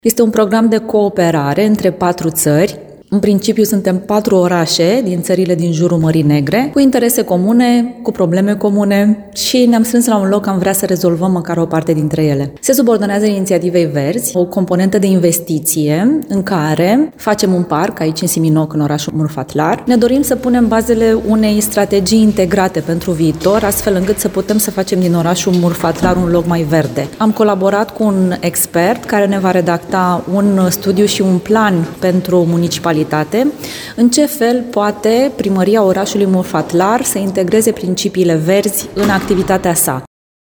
La Casa de Cultură din orașul Murfatlar s-a desfășurat astăzi, începând cu ora 12.00, o conferință de presă, pe tema proiectului european “Zone curate, străzi verzi și deschise” în care este inclus și orașul Murfatlar.